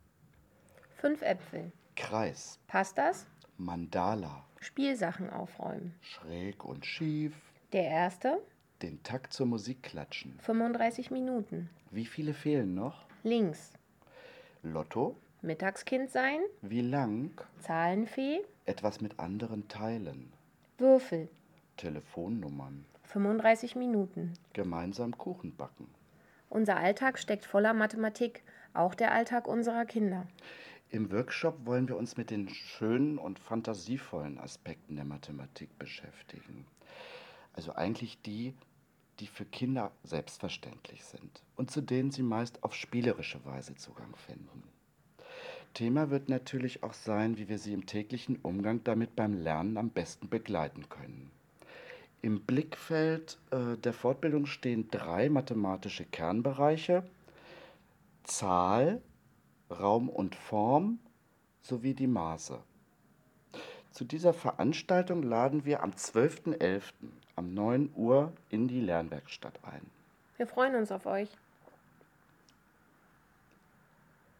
Das Werkstattteam berichtet in kurzen Audiobeiträgen über alles Wissenswerte rund um einzelne Veranstaltungen.